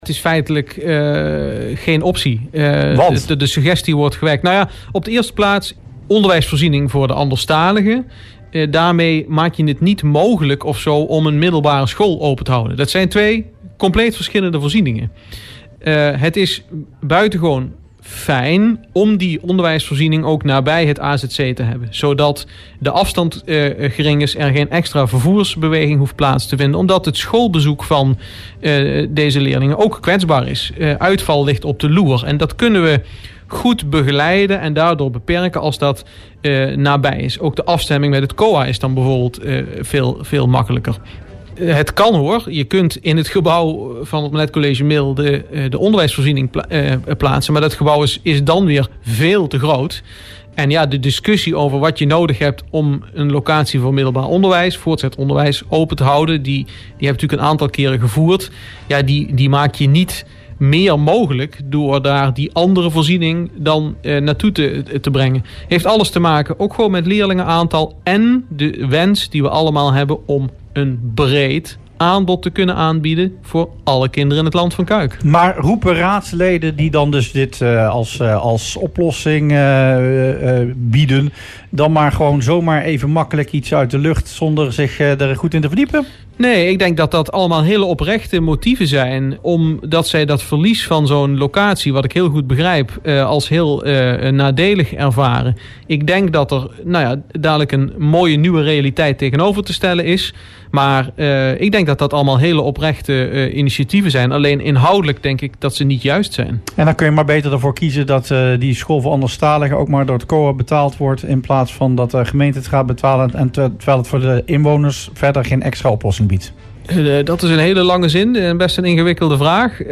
Dat zei wethouder Joost Hendriks van jeugd, onderwijs en cultuur in het politieke programma Regelkevers.
Wethouder Joost Hendriks in Regelkevers